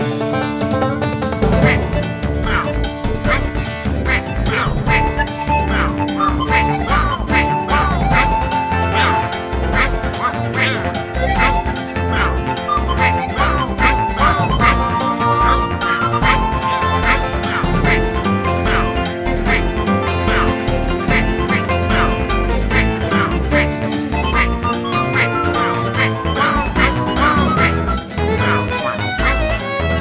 Miner and music
contribs)Fade-out.